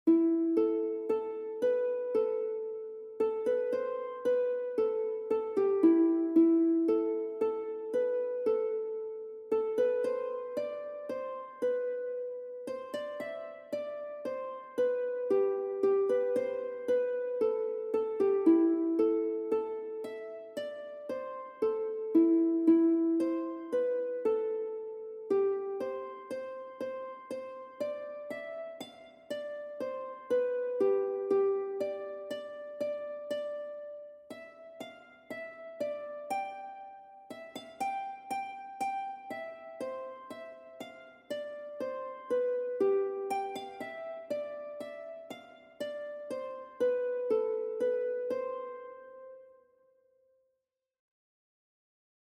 Featured Harp Music